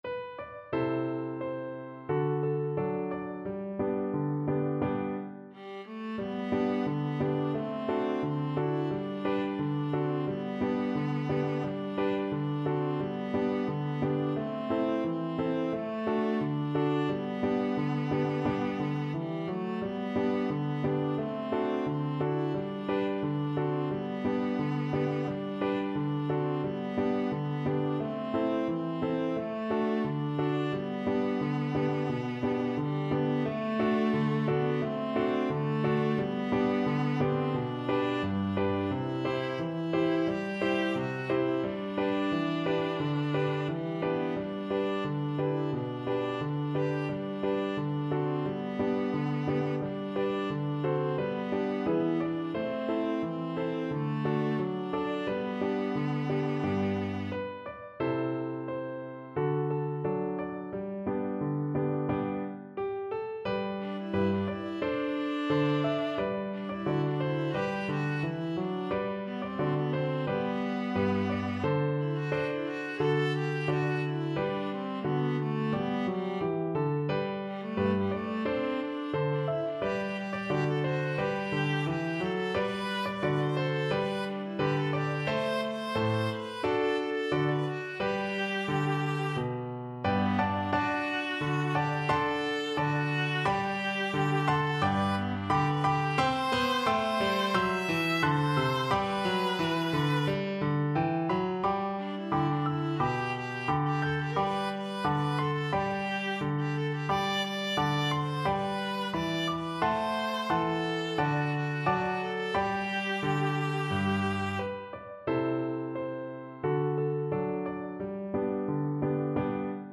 Viola version
4/4 (View more 4/4 Music)
Andante =c.88